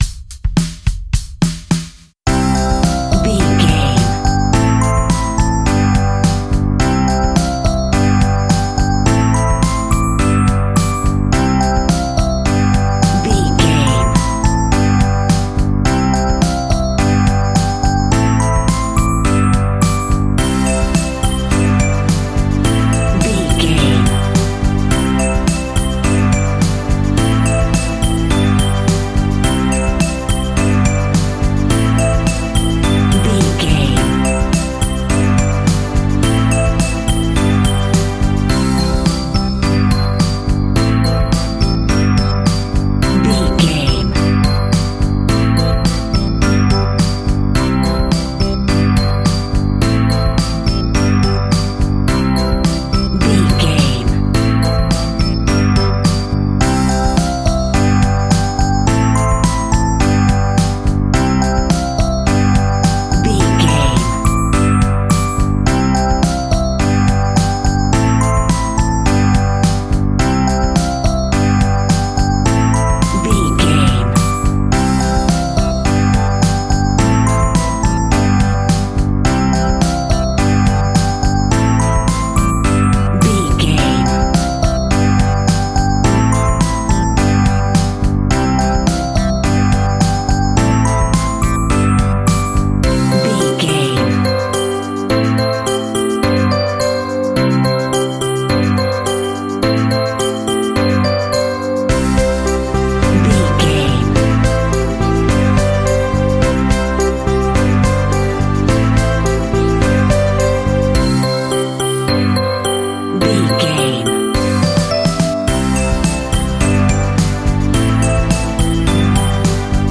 Car Toy Kids Music Cue.
Uplifting
Ionian/Major
instrumentals
childlike
cute
happy
kids piano